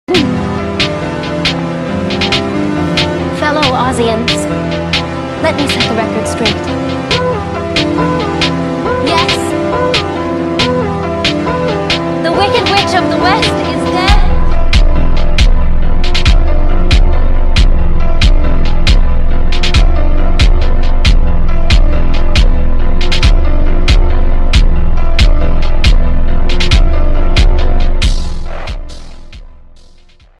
sorry abt the quality